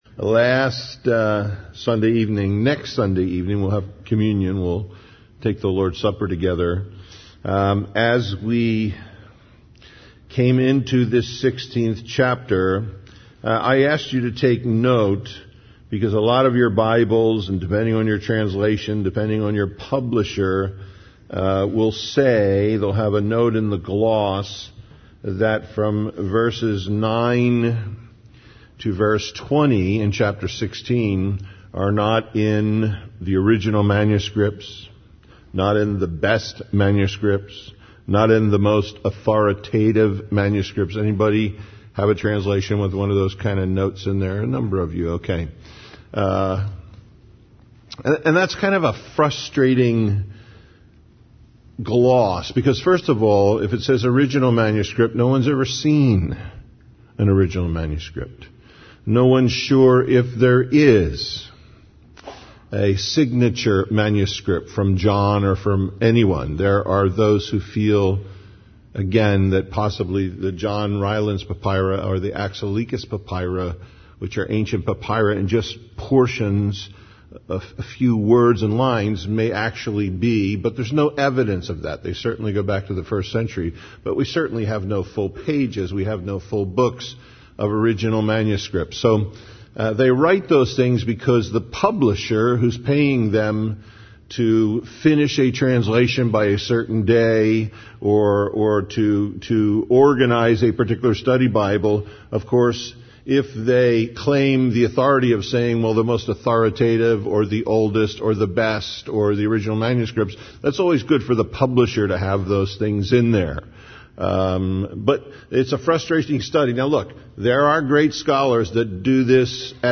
Mark 16:9-16:20 Listen Download Original Teaching Email Feedback 16 Now when Jesus was risen early the first day of the week, he appeared first to Mary Magdalene, out of whom he had cast seven devils.